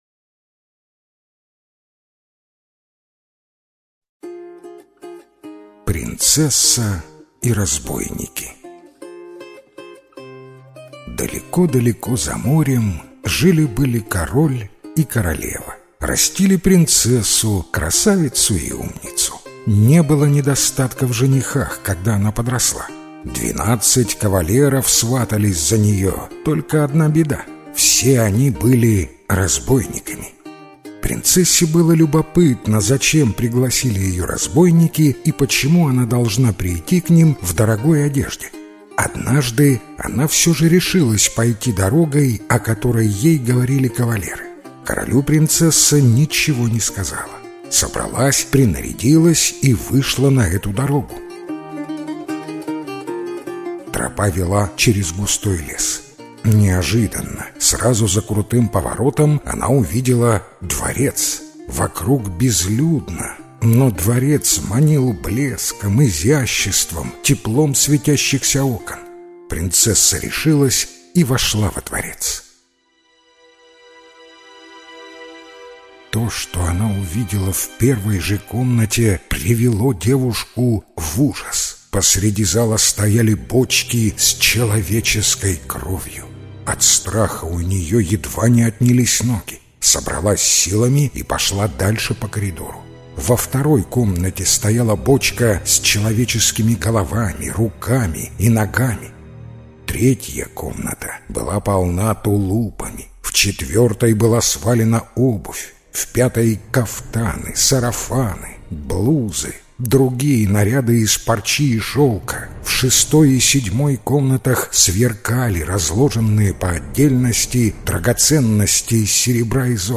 Принцесса и разбойники - белорусская аудиосказка. Сказка про принцессу, к которой сваталось много женихов, но все они были разбойники.